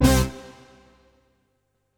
Synth Stab 03 (C).wav